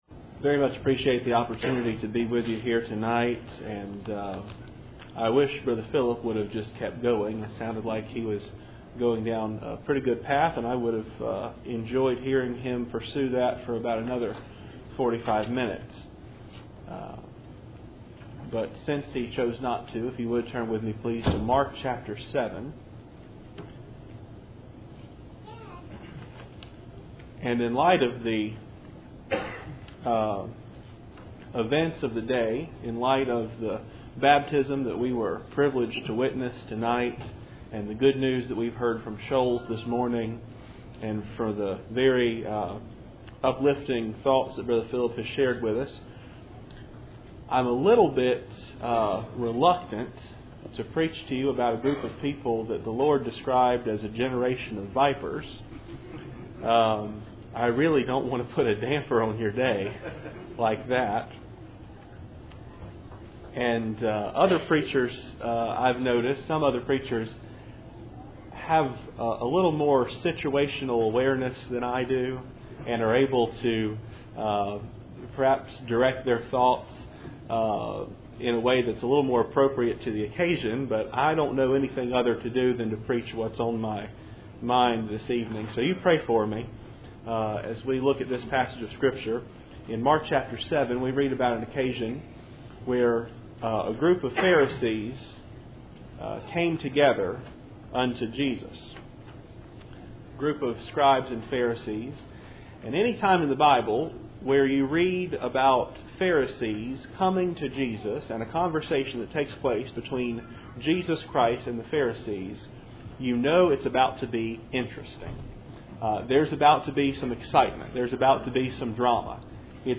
Mark 7:1-23 Service Type: Cool Springs PBC Sunday Evening %todo_render% « Joseph and Christ